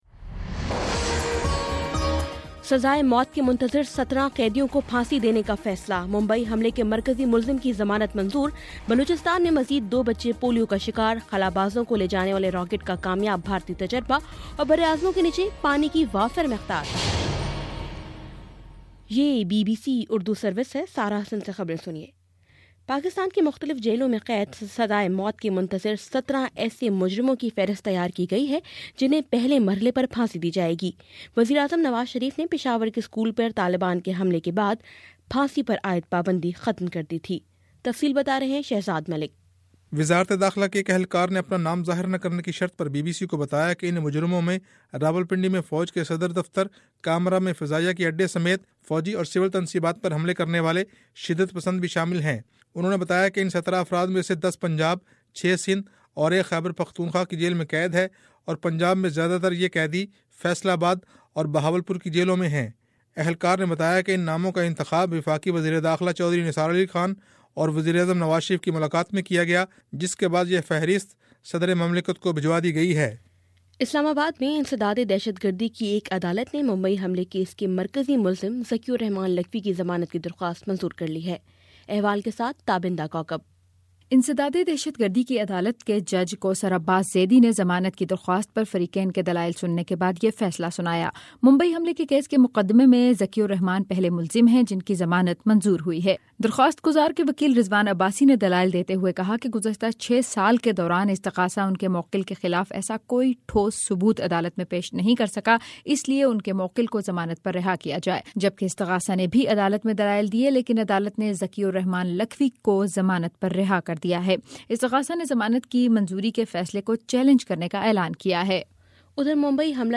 دسمبر 18 : شام سات بجے کا نیوز بُلیٹن